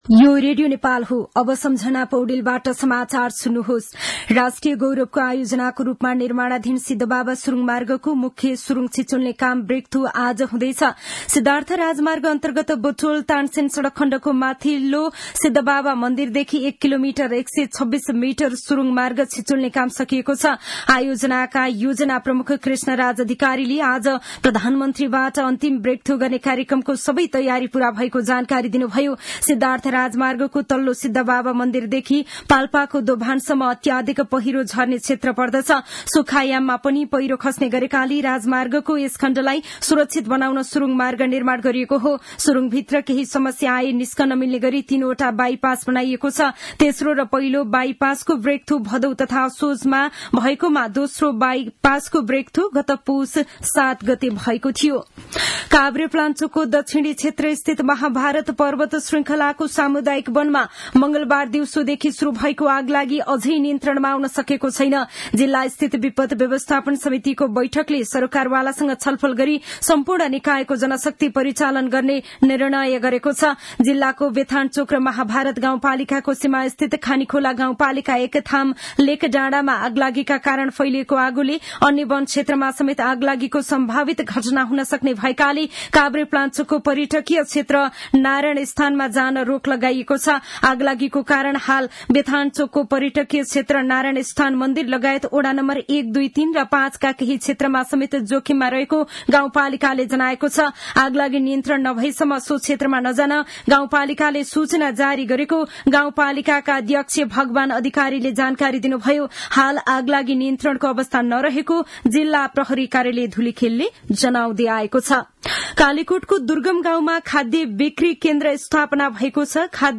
दिउँसो १ बजेको नेपाली समाचार : १२ माघ , २०८१
1-pm-news-1-10.mp3